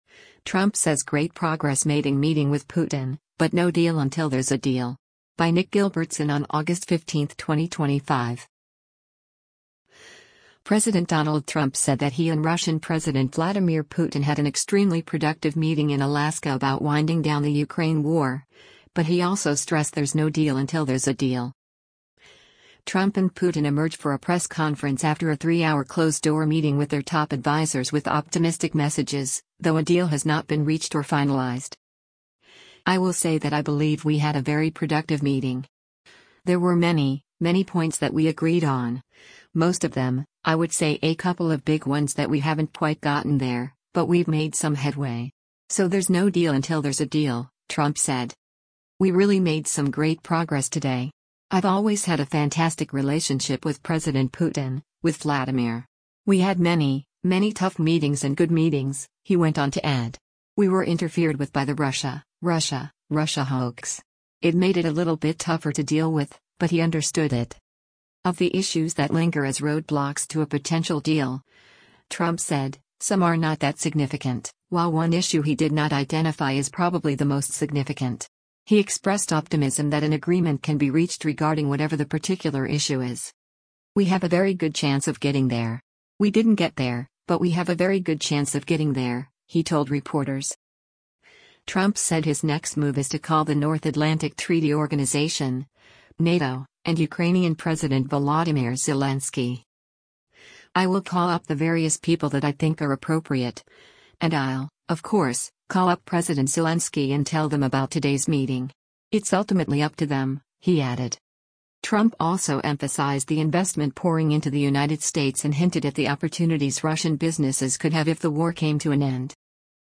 Trump and Putin emerged for a press conference after a 3-hour closed-door meeting with their top advisers with optimistic messages, though a deal has not been reached or finalized.